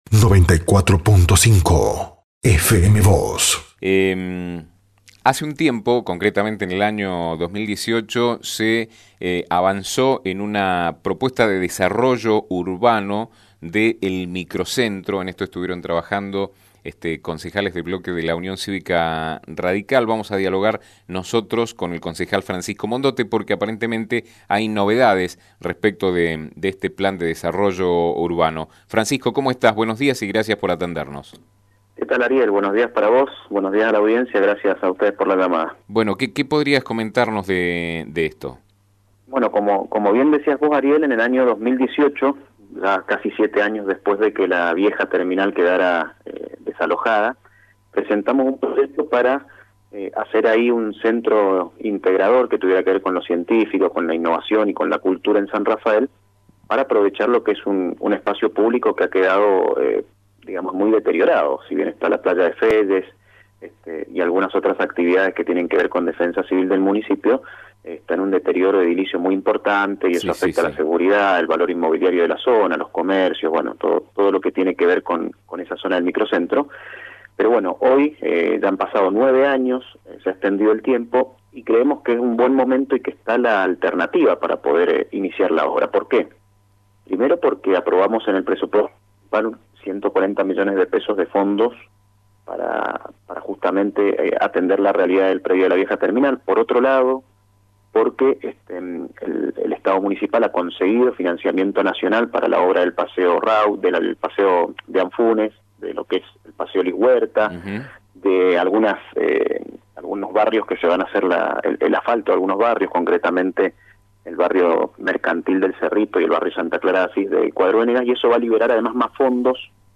En el año 2018, desde el bloque opositor del Concejo Deliberante, se avanzó en una propuesta de desarrollo urbano del microcentro sanrafaelino. Hubo novedades al respecto y sobre ello brindó detalles a FM Vos (94.5) y Diario San Rafael, el concejal Francisco Mondotte (UCR).